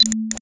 program_confirm.wav